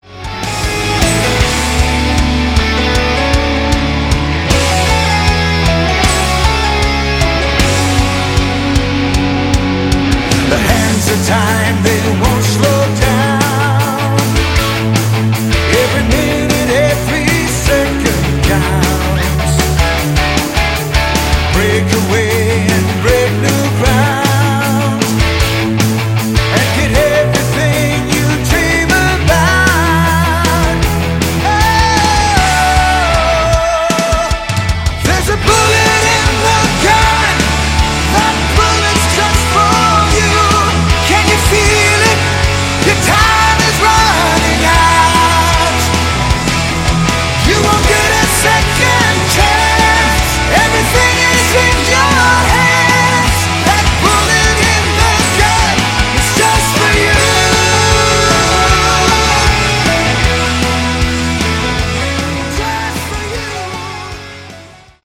Category: AOR
lead vocals
lead and rhythm guitar, bass, keyboards, backing vocals
drums
Hammond B3, piano, analog synthesizers